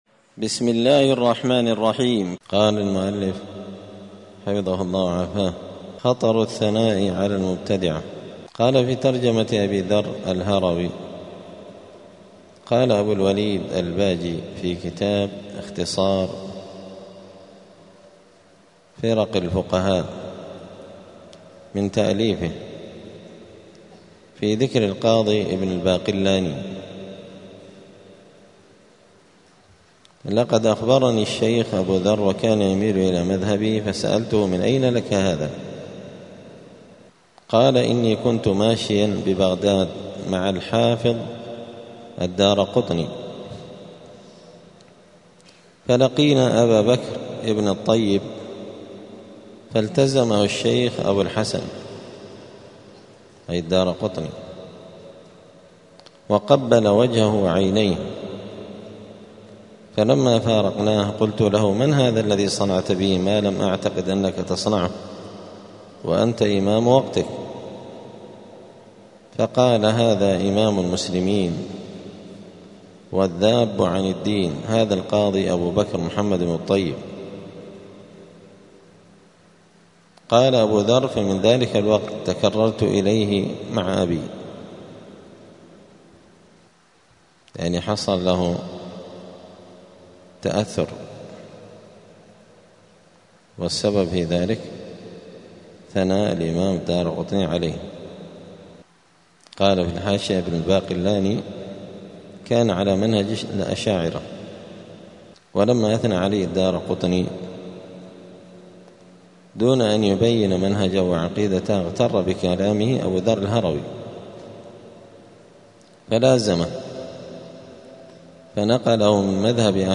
دار الحديث السلفية بمسجد الفرقان بقشن المهرة اليمن
*الدرس الثاني والثمانون (82) {خطر الثناء على المبتدعة}*